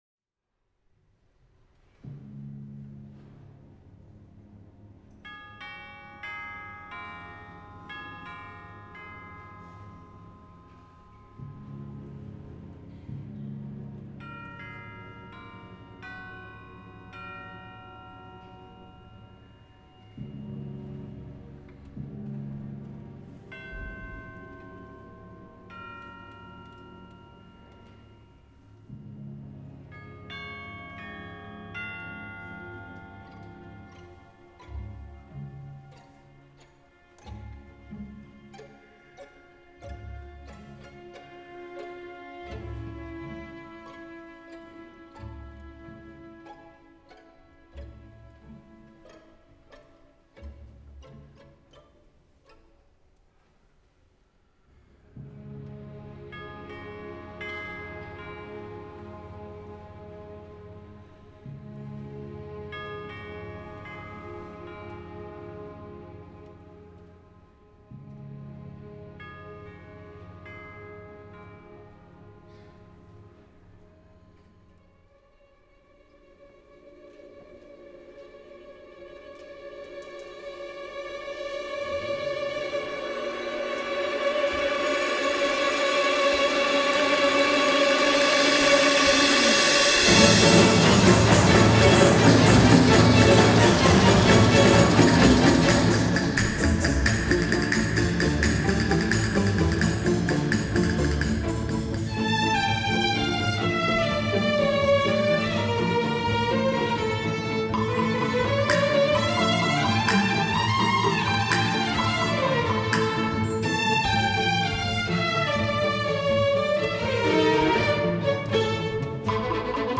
C. Ph. E. Bach: d-moll fuvolaverseny, Wq. 22 – III. tétel, Allegro di molto
E. Bach: d-moll fuvolaverseny, Wq. 22 – III. tétel, Allegro di molto A Liszt Ferenc Kamarazenekar és Emmanuel Pahud (fuvola) előadásában 2018. április 8-án a Zeneakadém…